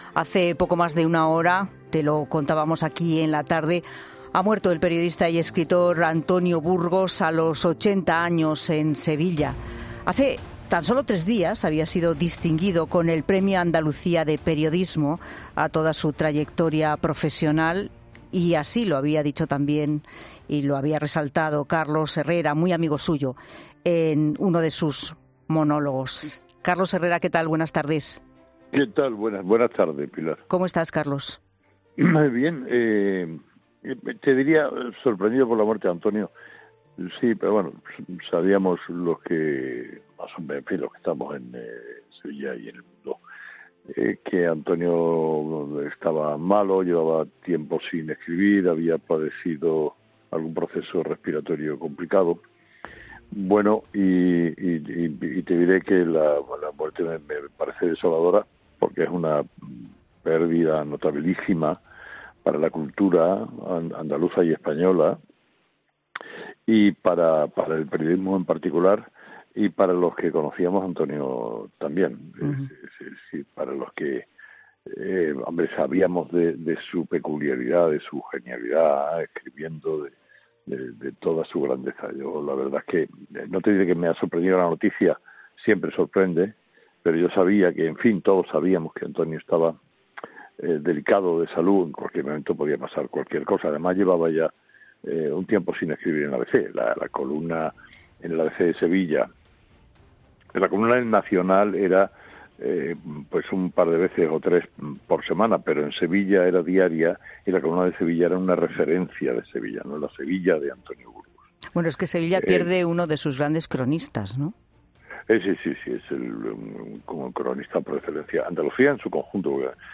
El director de Herrera en COPE les ha confesado a Fernando de Haro y Pilar Cisneros la gran amistad que le unía con Burgos.